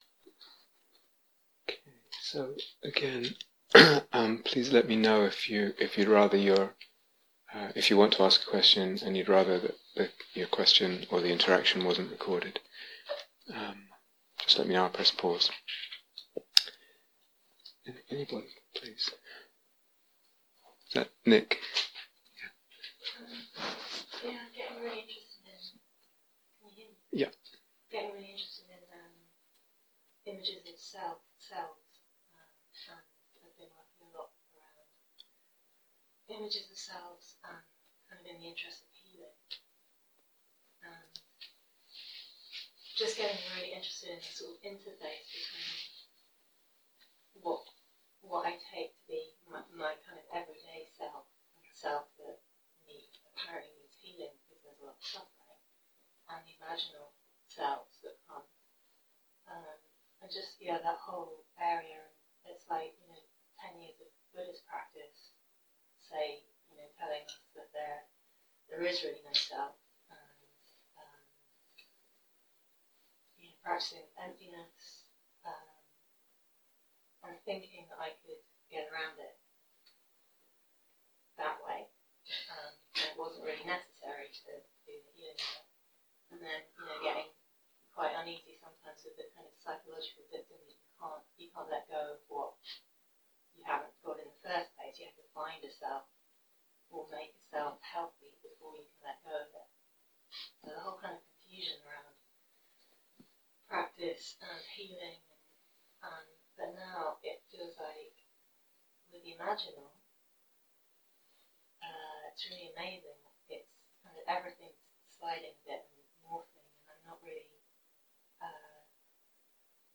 Transformation in Soul (Q & A)